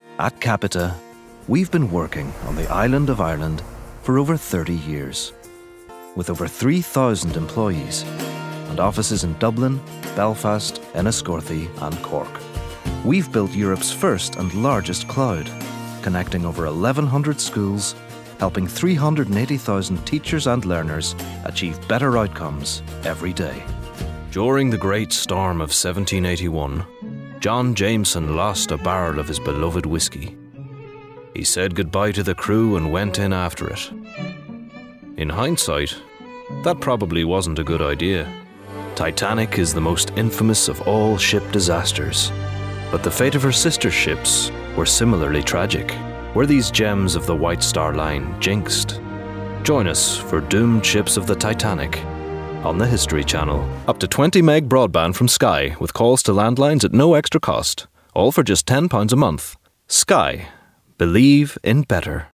Irish, Northern Irish
Commercial, Showreel